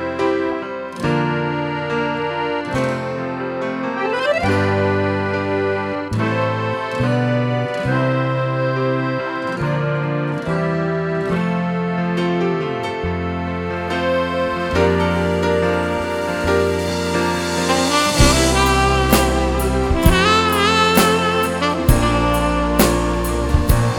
Pop (1980s)